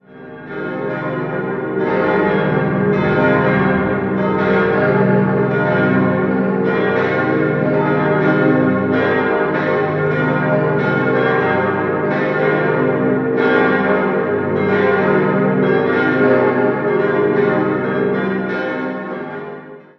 Am Ostchor stehen zwei monumental Türme, die schon von Weitem auf die Kirche aufmerksam machen. 5-stimmiges Geläute: as°-c'-es'-f'-g' Alle Glocken wurden vom Bochumer Verein für Gussstahlfabrikation gegossen: Die vier kleineren Glocken schon 1951, die große kam 1957 hinzu.